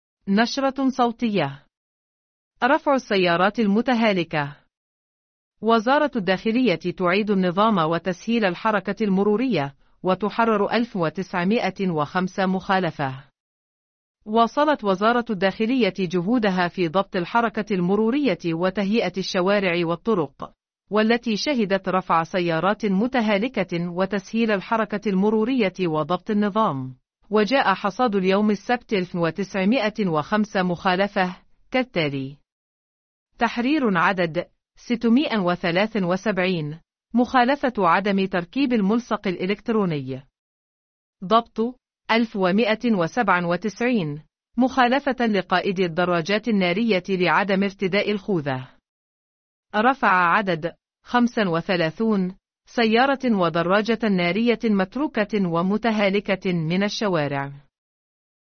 نشرة صوتية..